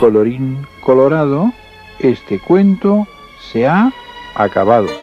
Versió radiofònica del conte "La caperutxeta vermella" de Charles Perrault amb motiu dels 300 anys de la seva publicació.